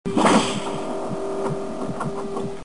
The Predators 'Eyes' focus on his target.